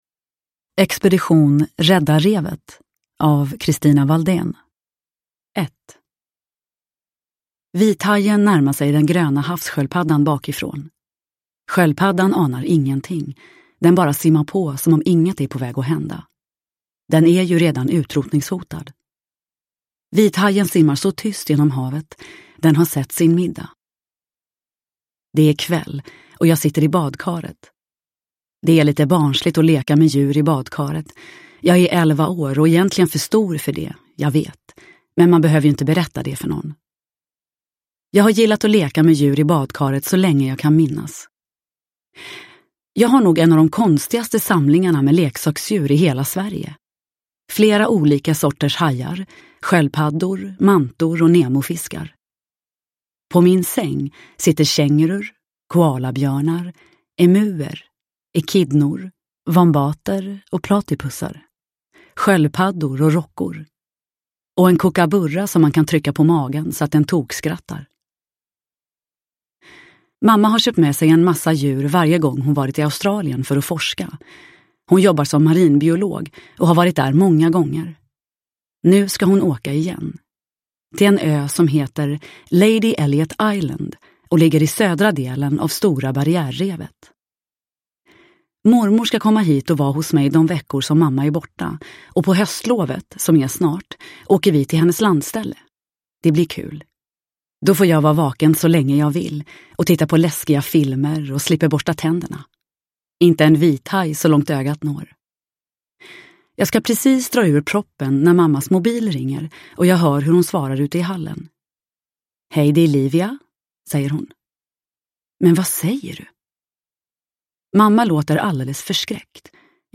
Expedition rädda revet – Ljudbok – Laddas ner
Uppläsare: Nina Zanjani